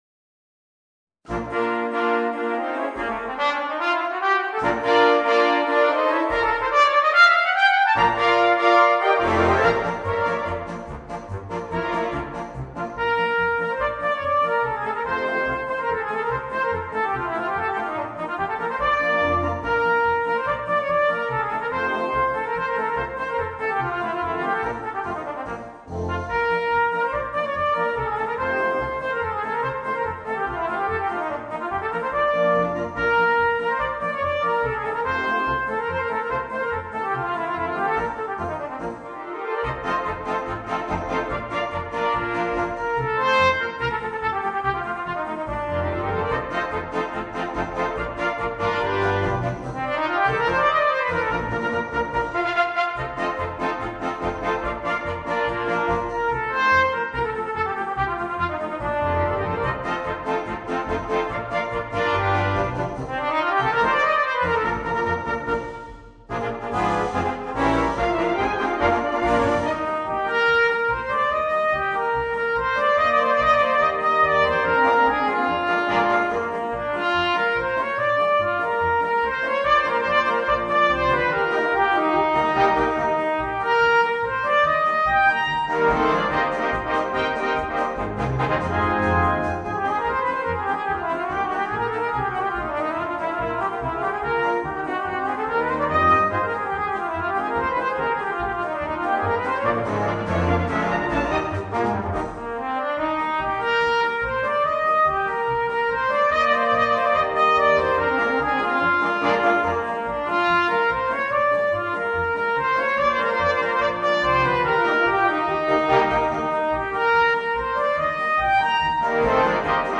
Voicing: Violoncello and Brass Band